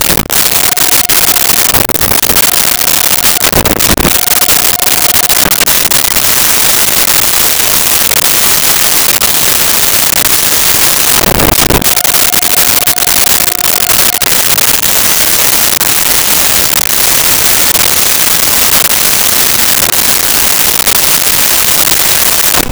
Printer Dot Matrix 02
Printer Dot Matrix 02.wav